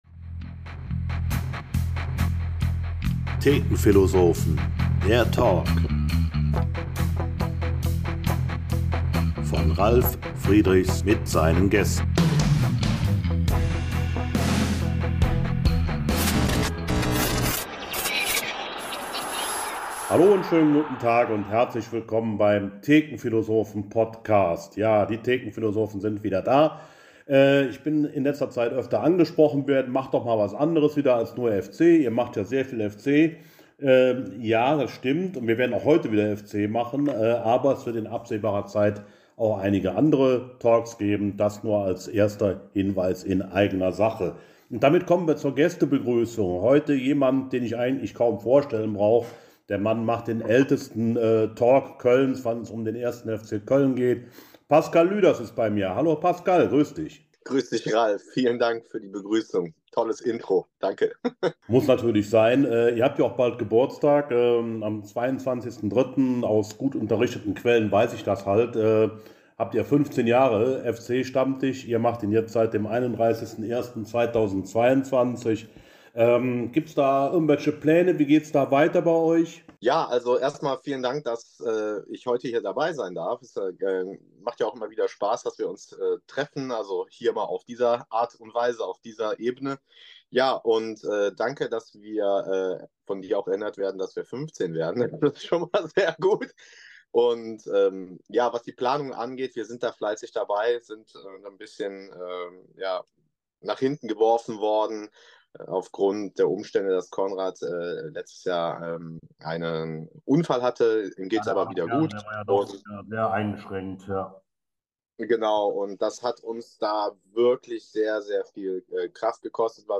Für die diesmal nicht ganz optimale Tonqualität wird hier um Entschuldigung gebeten!